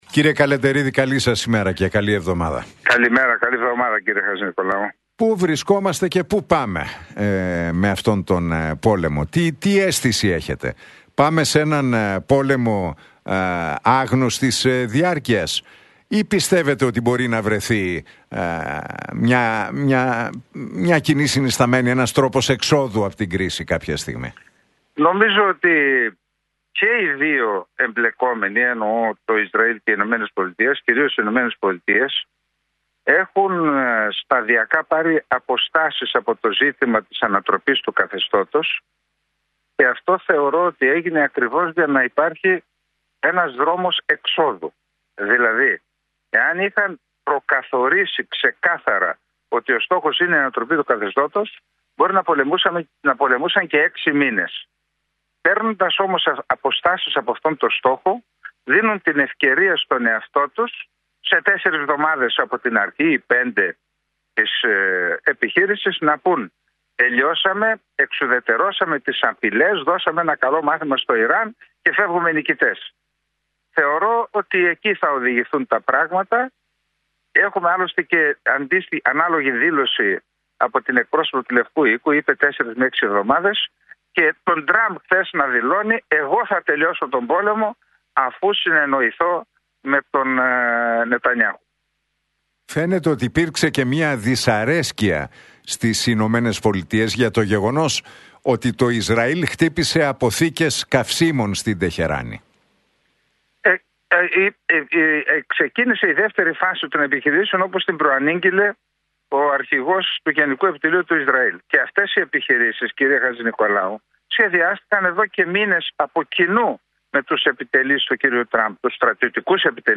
στον Realfm 97,8 και την εκπομπή του Νίκου Χατζηνικολάου